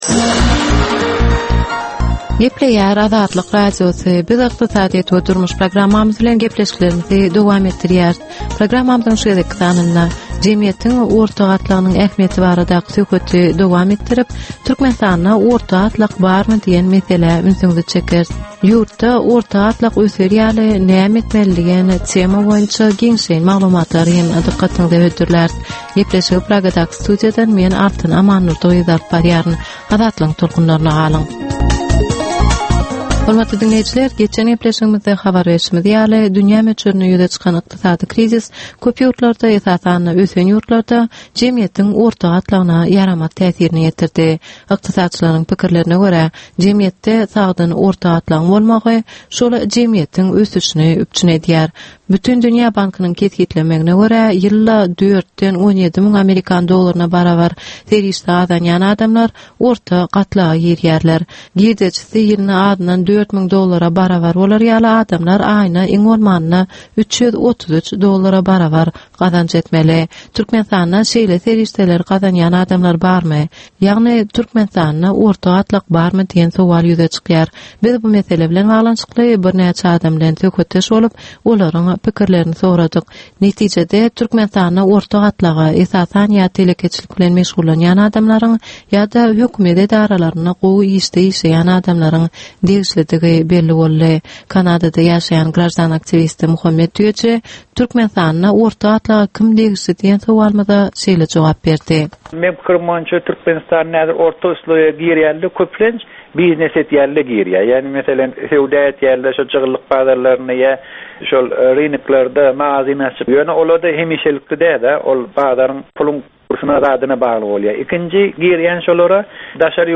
Türkmenistanyn ykdysadyýeti bilen baglanysykly möhüm meselelere bagyslanylyp taýýarlanylýan 10 minutlyk ýörite geplesik. Bu geplesikde Türkmenistanyn ykdysadyýeti bilen baglanysykly, seýle hem dasary ýurtlaryñ tejribeleri bilen baglanysykly derwaýys meseleler boýnça dürli maglumatlar, synlar, adaty dinleýjilerin, synçylaryn we bilermenlerin pikirleri, teklipleri berilýär.